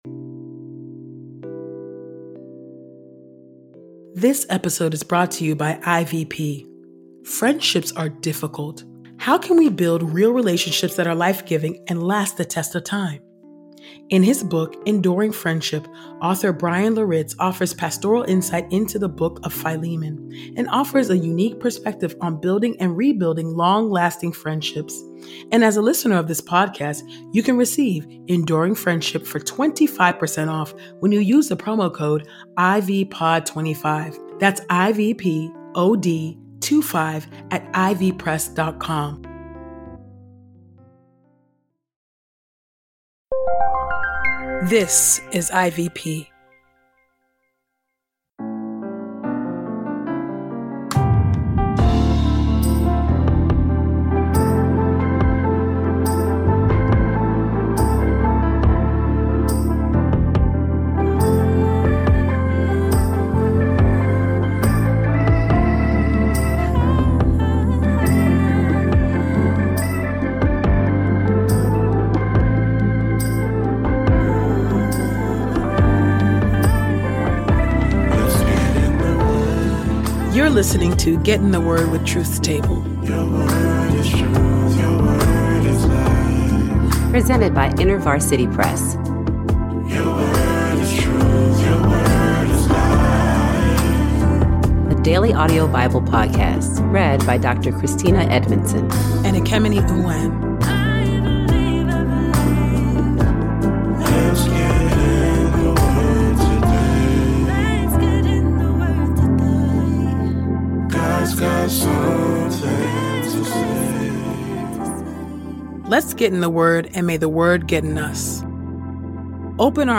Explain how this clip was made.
Sound engineering is from Podastery Studios